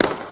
pew.ogg